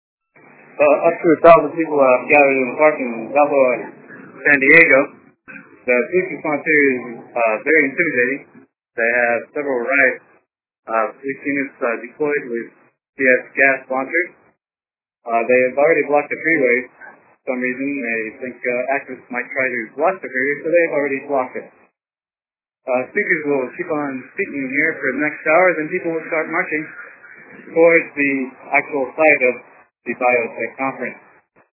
Teach-ins happening in Balboa Park, heavy police presence, the highways have been blocked off by police - listen to this report from San Diego